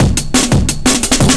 Case Closed 175bpm.wav